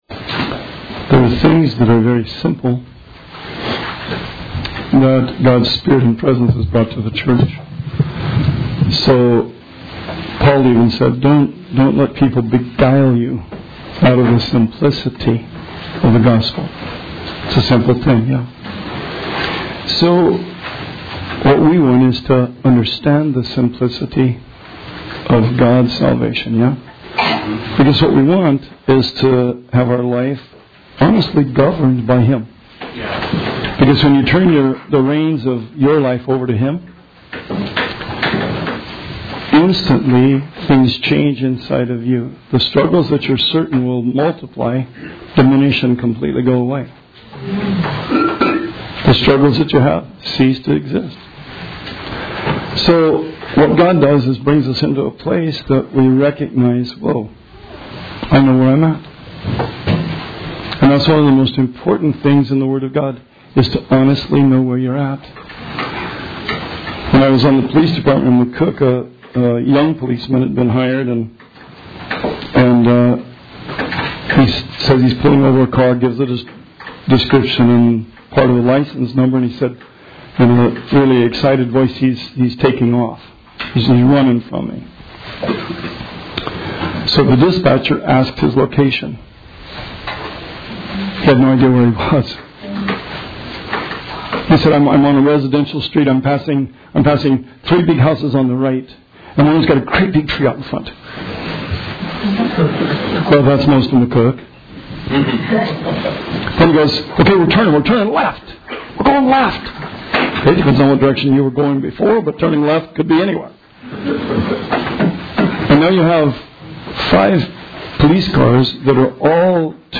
Sermon 6/23/19 – RR Archives